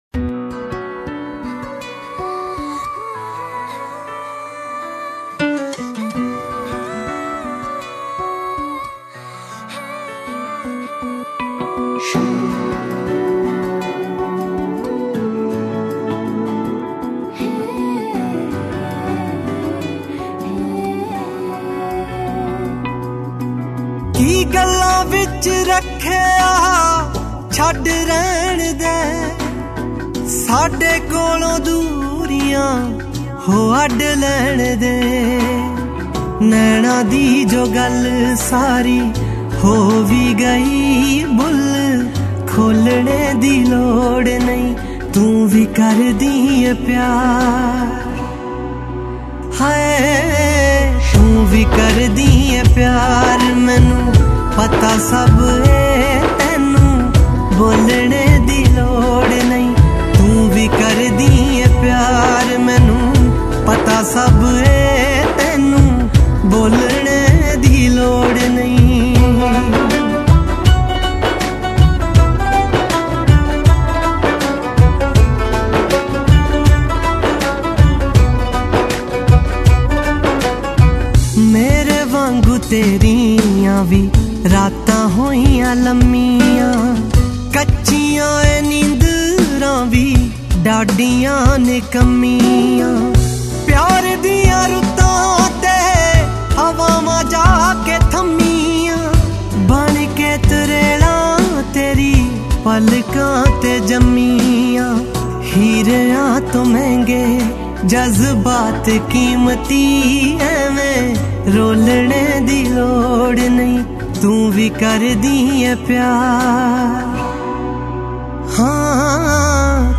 Punjabi Bhangra MP3 Songs
64 Kbps Low Quality